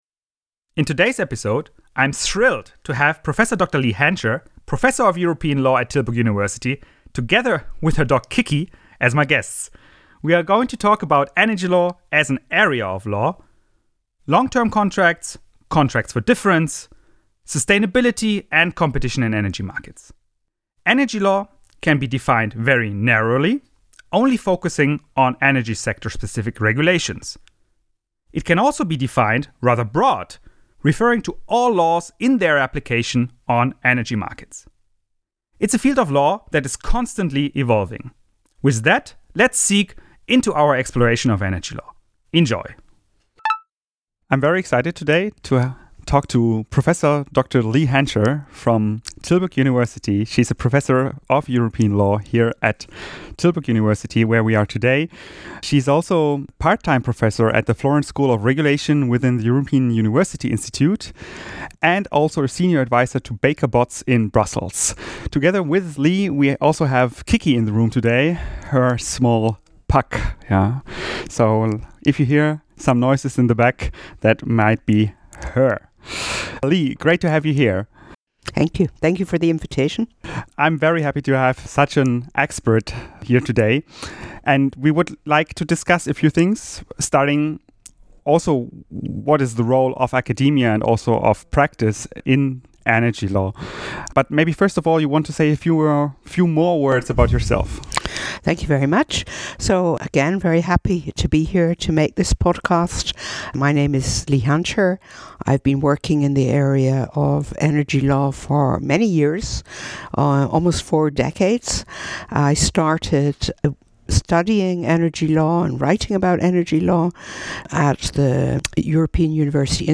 The interview took place on 27 November 2023.